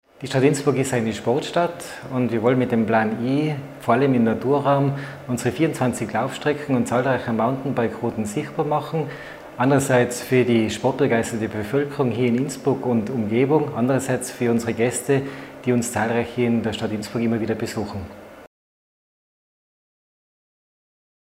O-Ton von Bürgermeisterin Christine Oppitz-Plörer